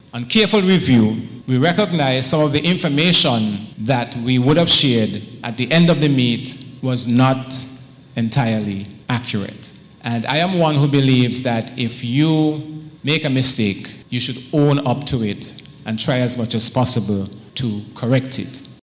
Permanent Secretary in the Ministry of Education (Nevis), Mr. Kevin Barrett.
His comments were made at a press briefing on May 11th, to announce the revised scores of the Gulf Insurance Inter-primary Schools Championship (or Mini-Olympics), which was held on April 27th.